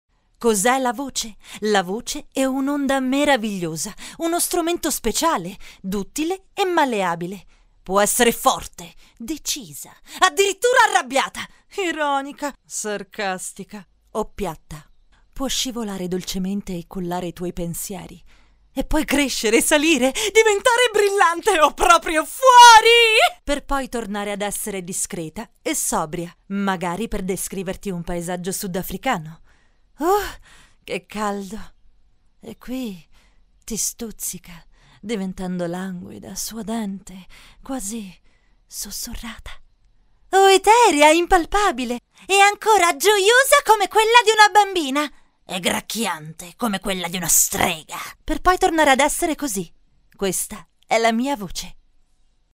Italian actress. voice actress and singer.
Kein Dialekt
Sprechprobe: Werbung (Muttersprache):